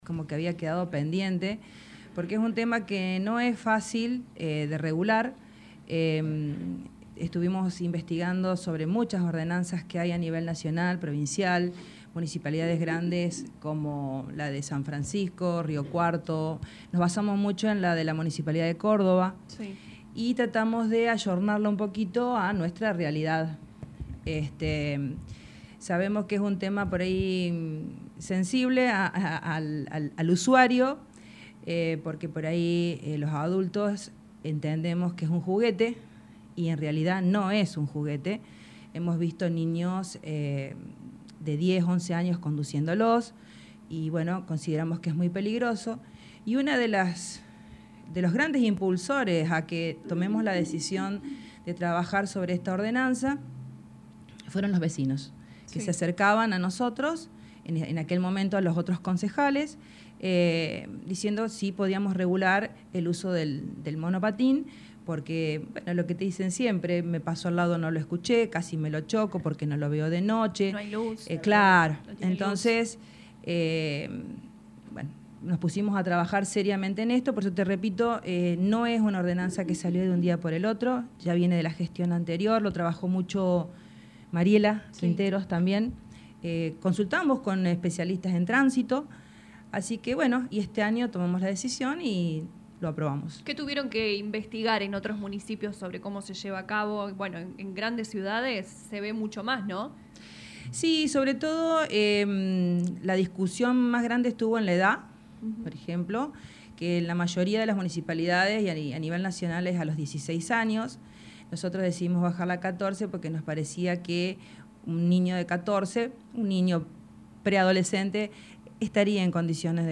Así lo confirmaron los concejales del oficialismo en diálogo con LA RADIO 102.9 FM.